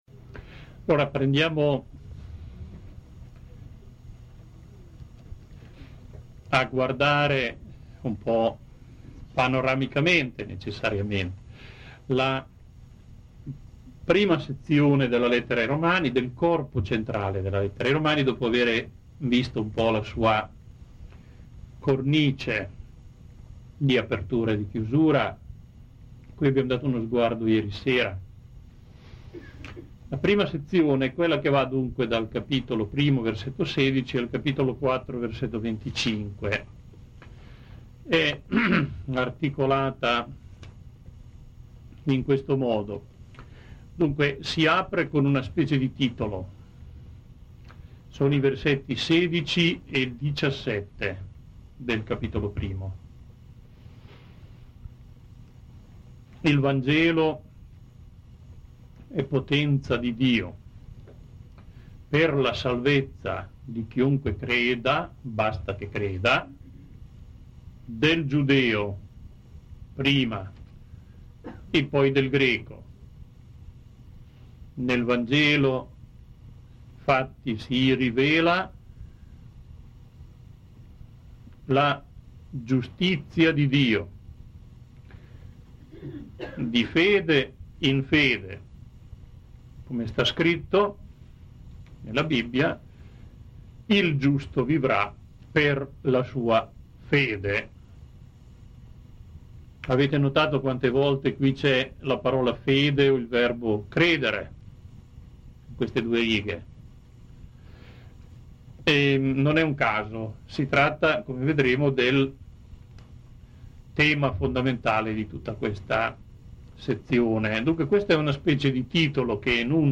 Contributi audio - registrazioni delle lezioni Argomento File audio 1 1° Lezione Click to download in MP3 format (17.06MB) 2 2 ° Lezione Click to download in MP3 format (20.06MB) 3 colspan="2" />3° Lezione File don't exists.